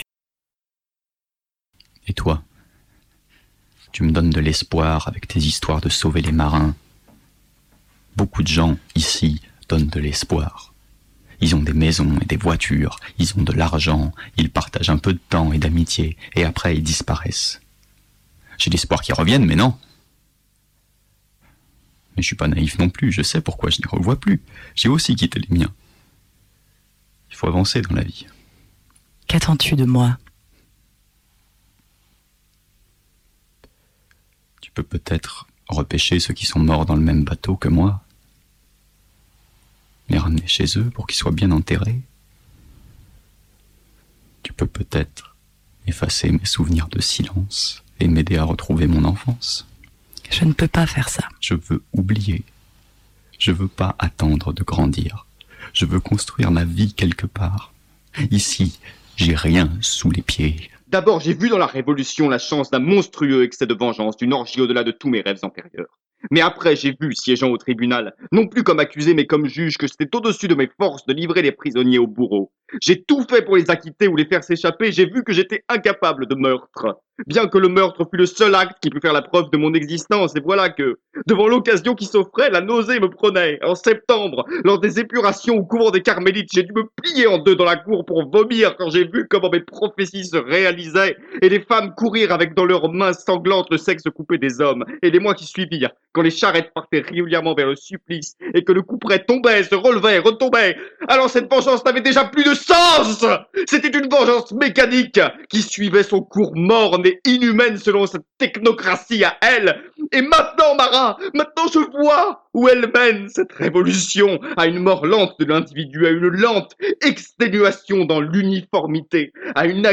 Bande-Démo Voix-Off